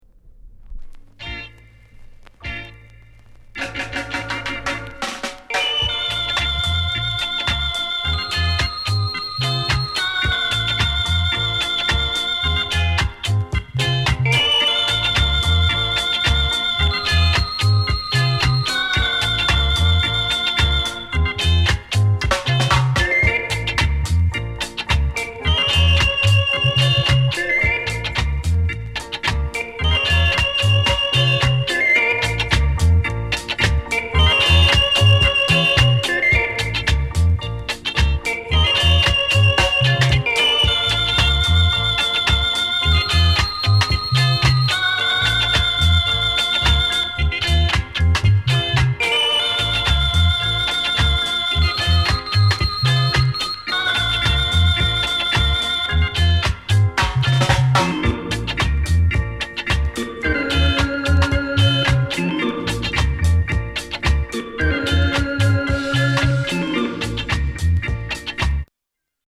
SKINHEAD